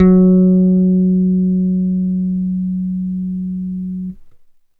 Index of /90_sSampleCDs/USB Soundscan vol.30 - Bass Grooves [AKAI] 1CD/Partition E/04-BASS MED